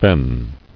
[fen]